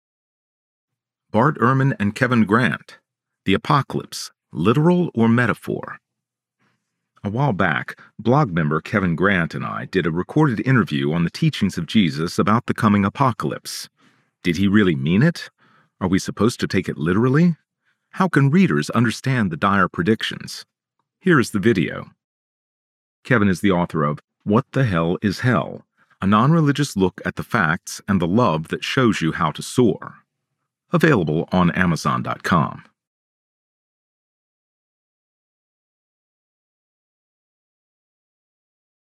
A recorded interview on the teachings of Jesus about the coming apocalypse.